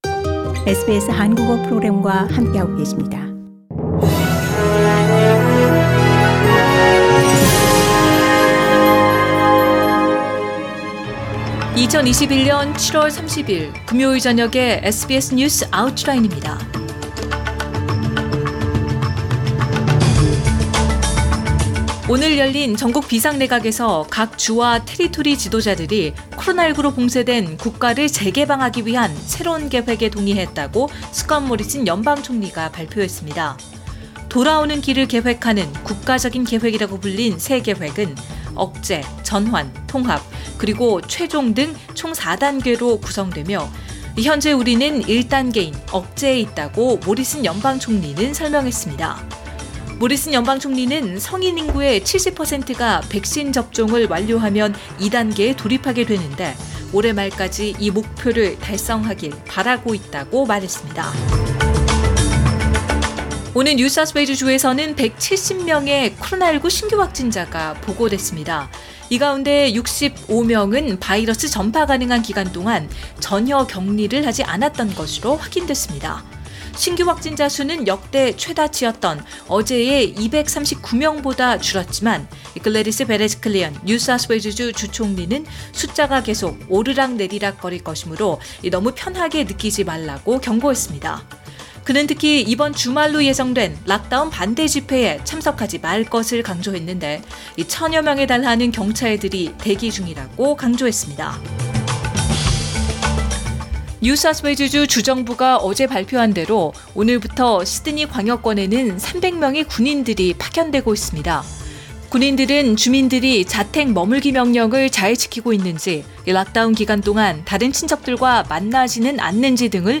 SBS News Outlines…2021년 7월 30일 저녁 주요 뉴스